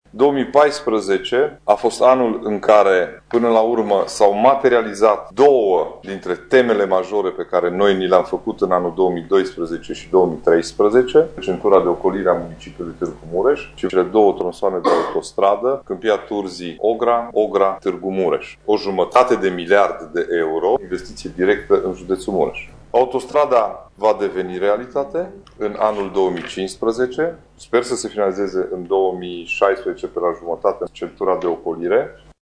Investiţia totală pentru cele două drumuri este de 500 de milioane de euro, a precizat preşedintele CJ Mureş, Ciprian Dobre, la întâlnirea de final de an pe care a avut-o astăzi cu reprezentanţii presei.